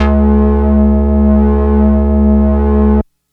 Analog Low C.wav